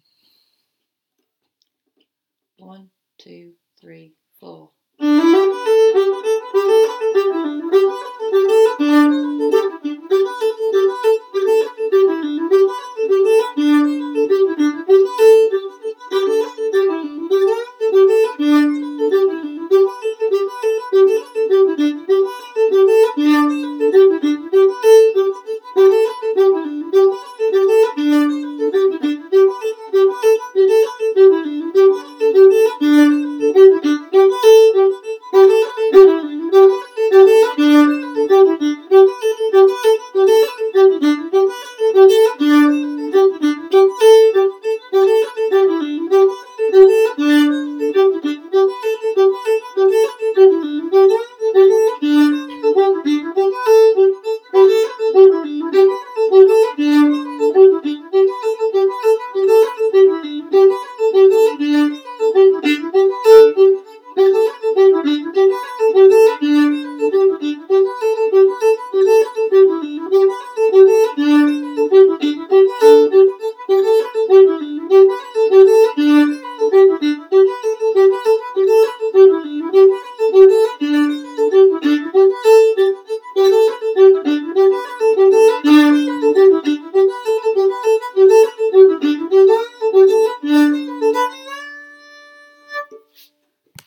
A Part Only